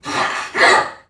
NPC_Creatures_Vocalisations_Robothead [15].wav